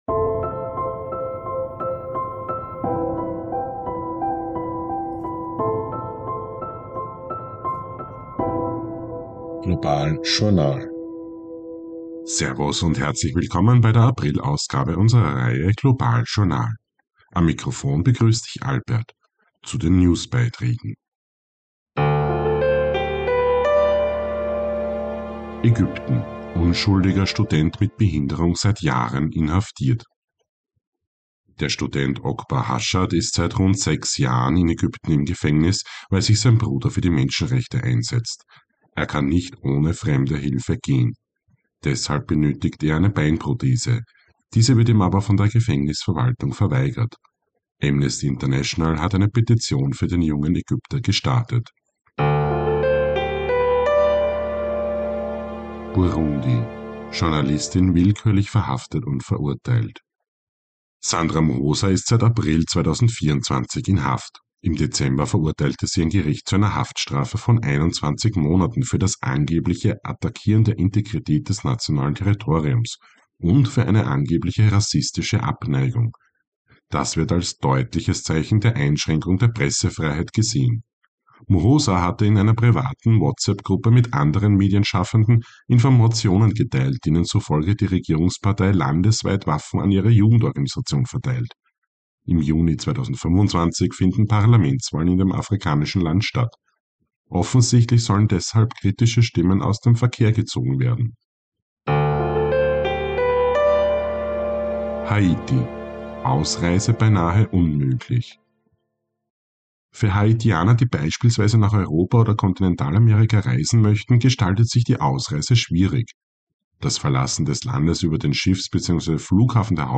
News Update April 2025